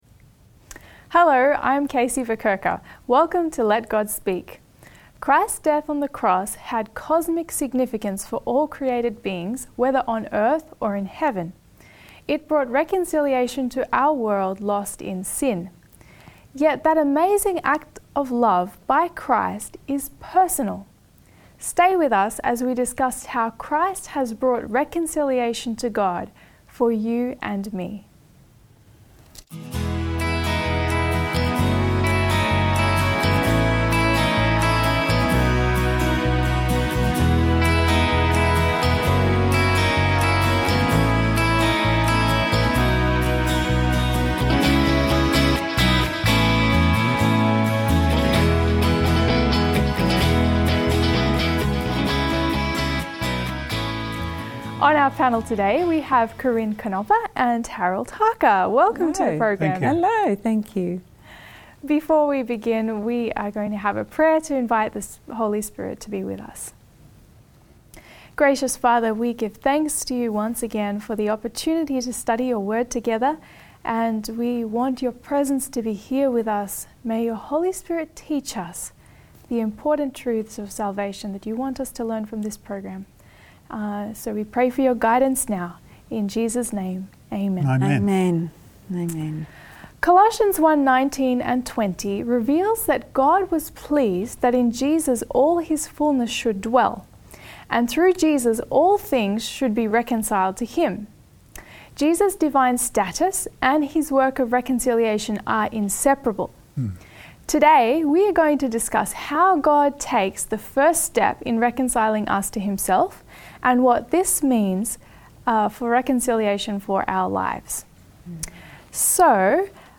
Join us for lively discussions and deep insights into the Word of God.